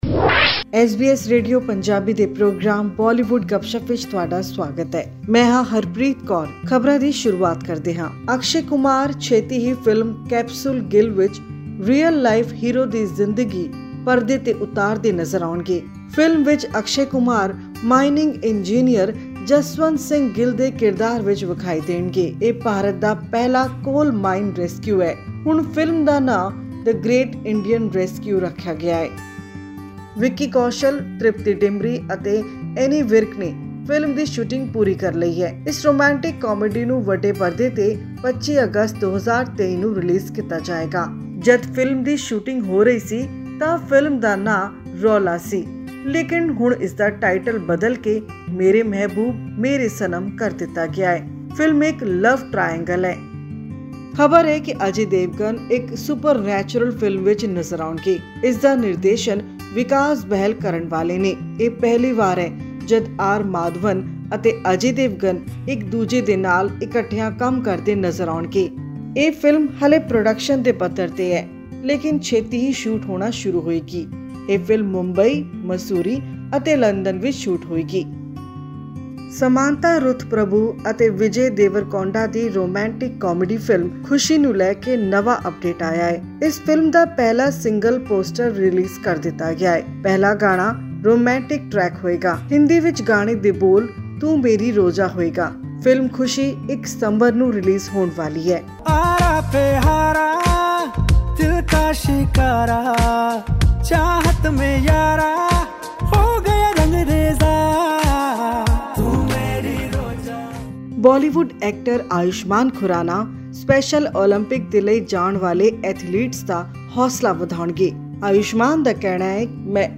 Jodi, a musical extravaganza has been screened over 125 locations across the globe and has earned over $734,000 in just one weekend. This and much more in our weekly news bulletin from Bollywood.